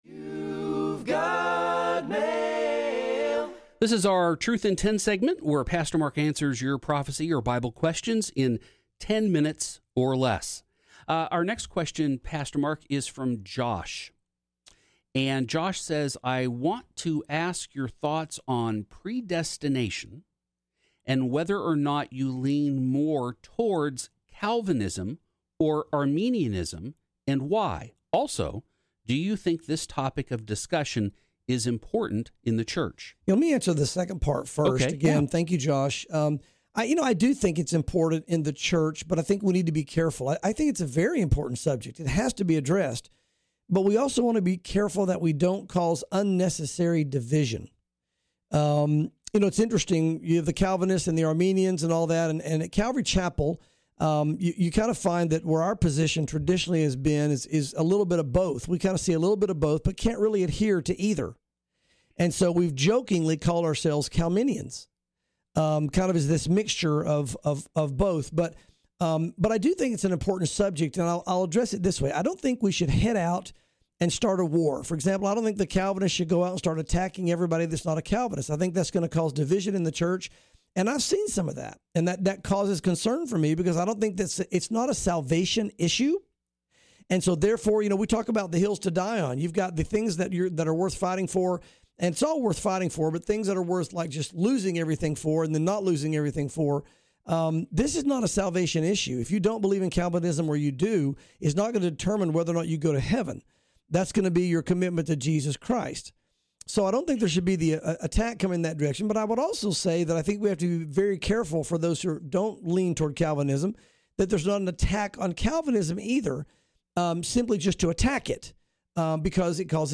| Truth in 10 download sermon mp3 download sermon notes Welcome to Calvary Chapel Knoxville!